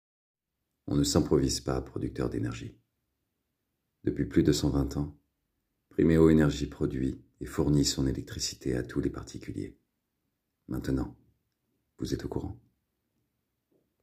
20 - 55 ans - Baryton Ténor